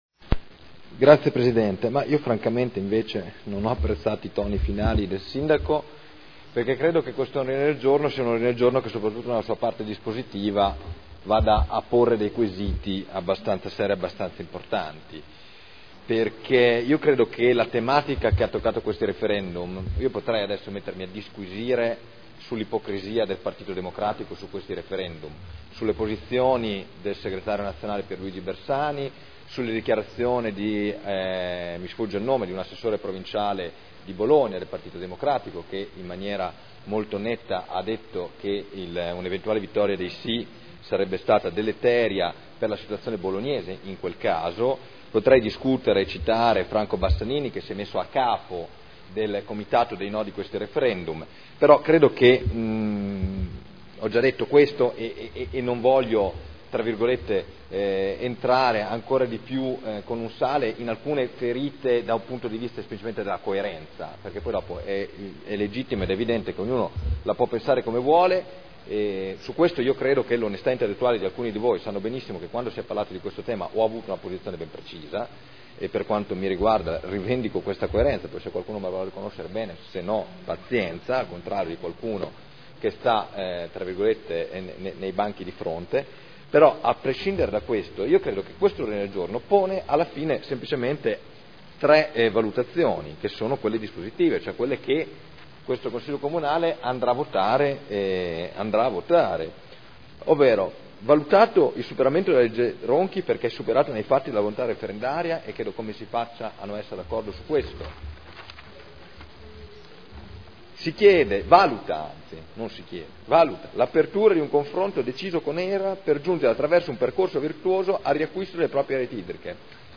Michele Barcaiuolo — Sito Audio Consiglio Comunale
Dibattito